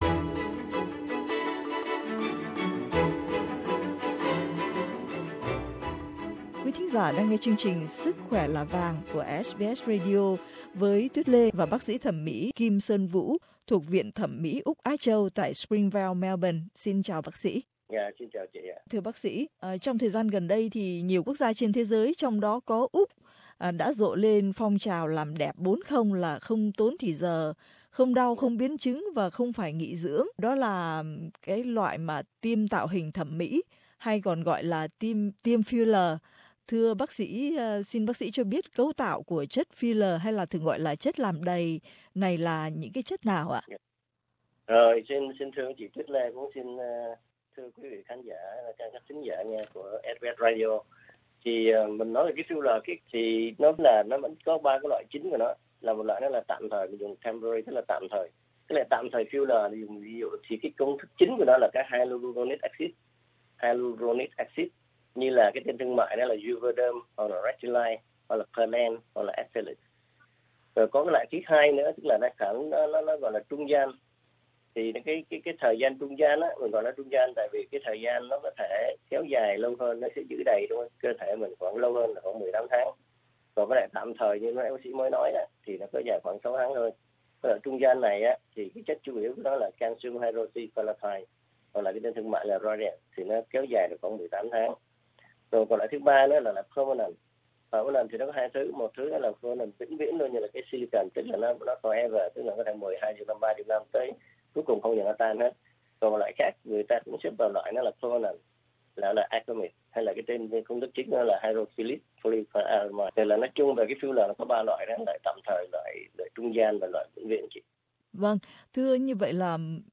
Xin bấm vào phần âm thanh để nghe tất cả phần hỏi chuyện